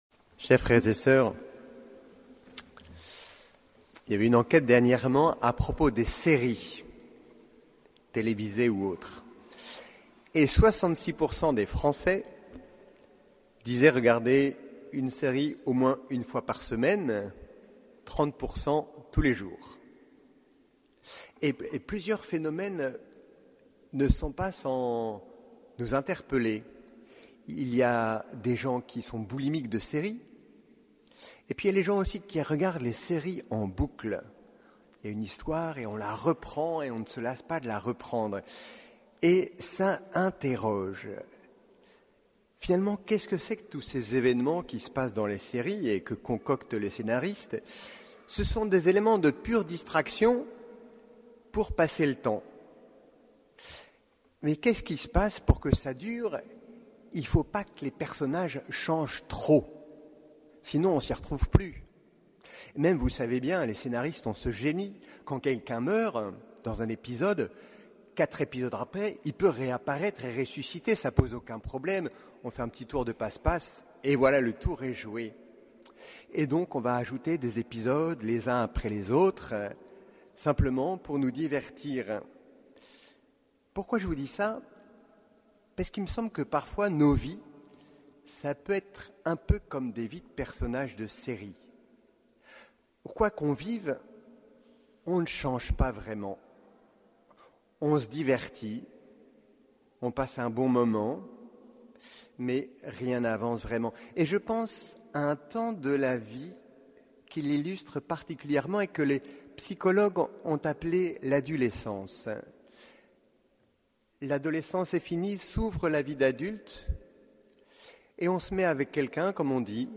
Homélie du 21e dimanche du Temps Ordinaire